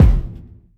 Kick
Original creative-commons licensed sounds for DJ's and music producers, recorded with high quality studio microphones.
Natural Bass Drum One Shot E Key 29.wav
short-reverb-bass-drum-one-shot-e-key-280-E12.wav